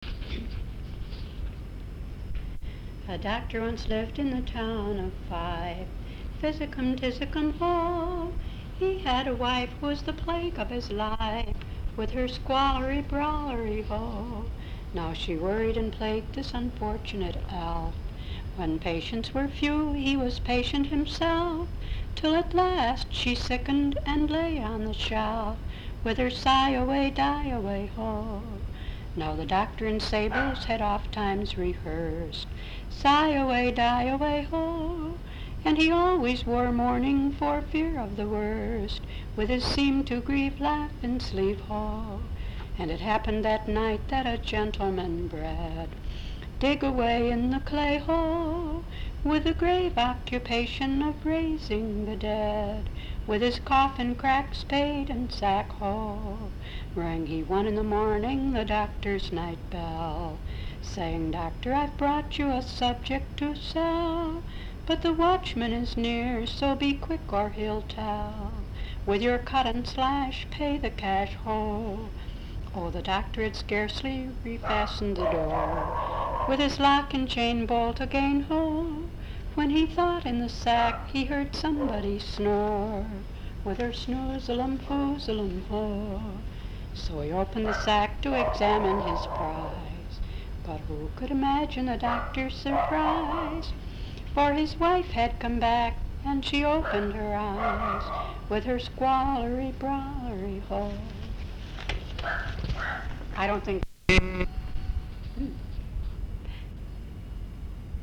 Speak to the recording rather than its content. sound tape reel (analog)